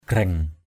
/ɡ͡ɣrɛŋ/ (cv.) hagraing h=g/ 1.